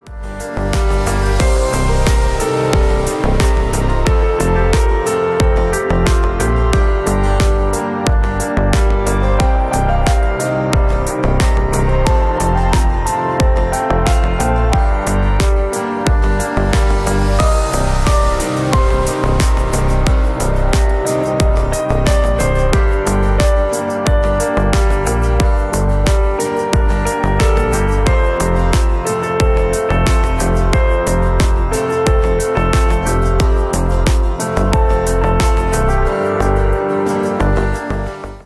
Ремикс # Поп Музыка # кавказские # клубные # без слов